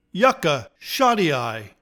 Pronounciation:
YUK-ka shot-TEE-eyee-LA-eye